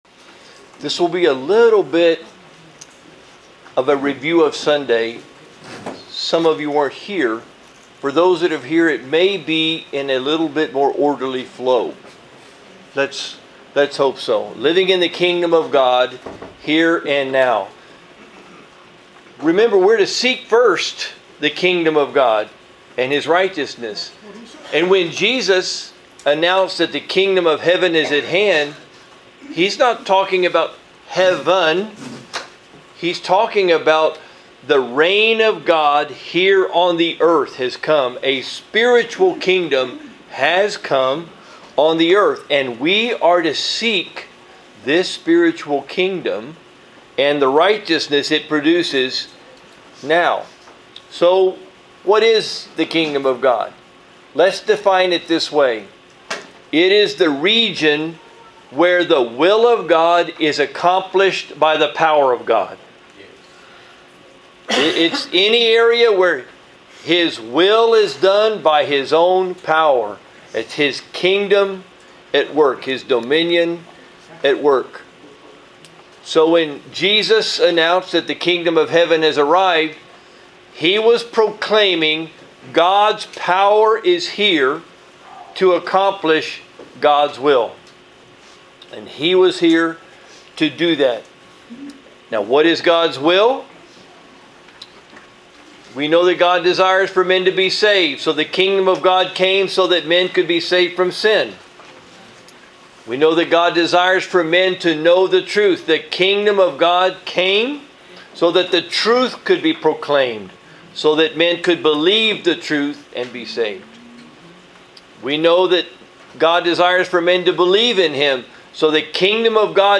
Wednesday Night Study - University Park Baptist